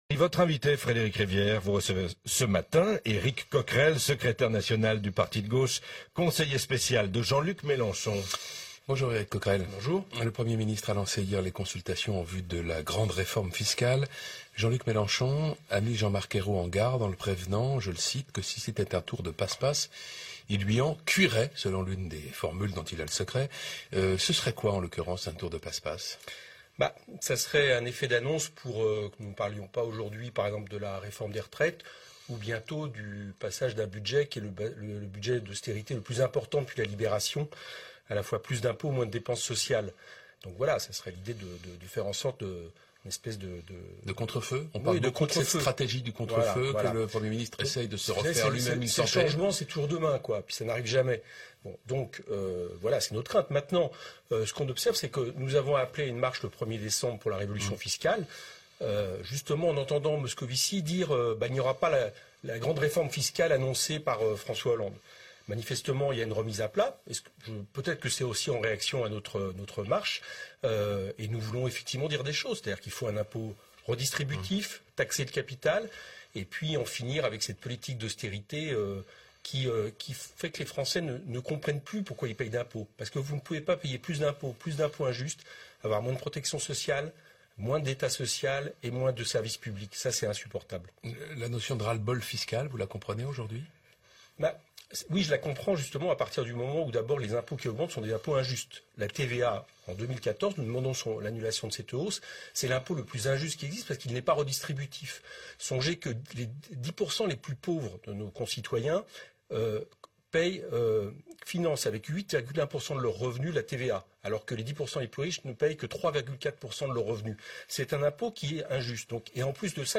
Eric Coquerel Éric Coquerel Éric Coquerel : Président de la Commission des finances à l’Assemblée nationale .. était l’invité de RFI.